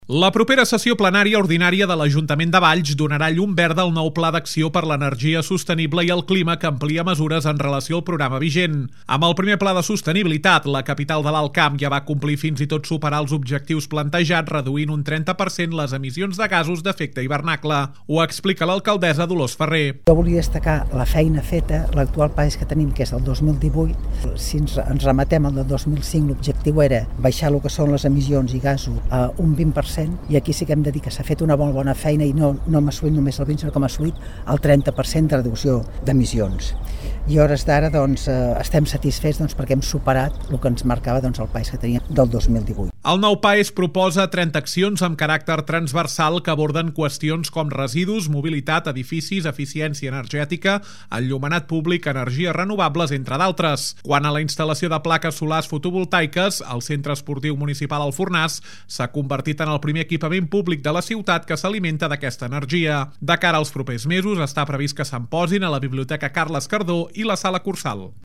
Amb el primer pla de sostenibilitat, la capital de l’Alt Camp ja va complir i fins i tot superar els objectius plantejats reduint un 30% les emissions de gasos d’efecte hivernacle.  Ho explica l’alcaldessa, Dolors Farré.